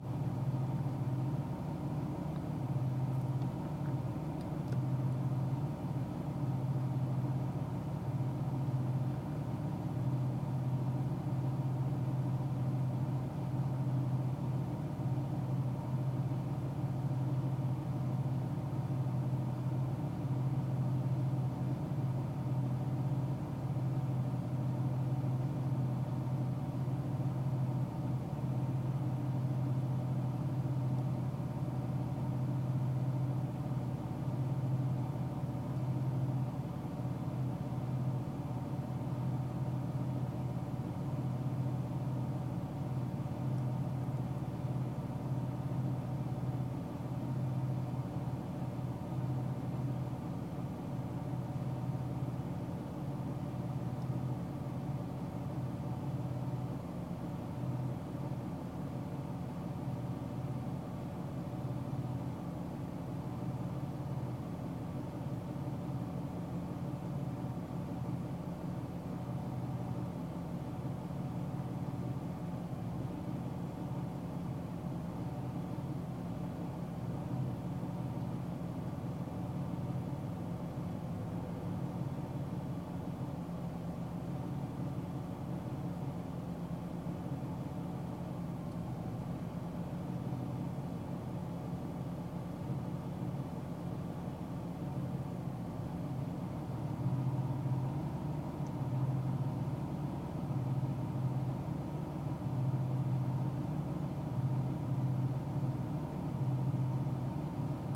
Car Interior.mp3